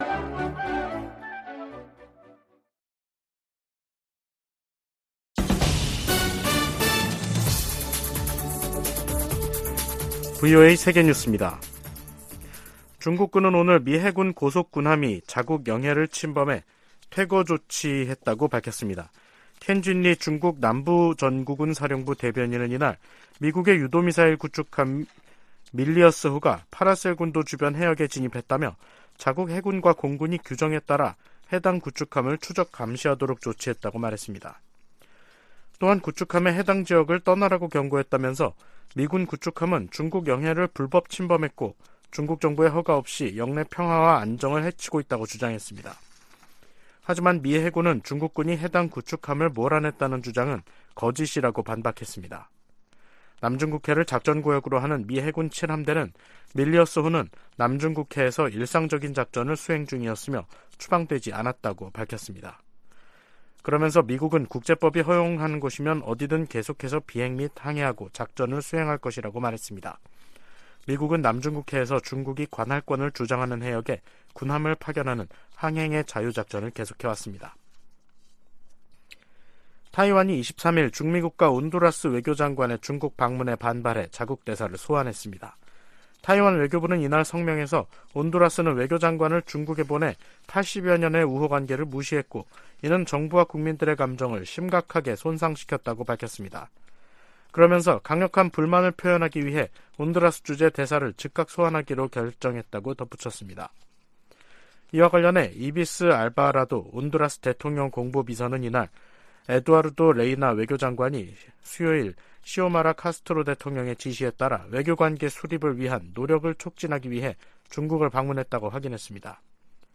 VOA 한국어 간판 뉴스 프로그램 '뉴스 투데이', 2023년 3월 23일 3부 방송입니다. 백악관은 북한의 핵 공격이 임박했다는 징후는 없지만 최대한 면밀히 주시하고 있다고 밝혔습니다. 북한은 국제사회의 비핵화 요구를 핵 포기 강요라며 선전포고로 간주하고 핵으로 맞서겠다고 위협했습니다. 미국 국무부가 한국 정부의 최근 독자 대북제재 조치에 환영의 입장을 밝혔습니다.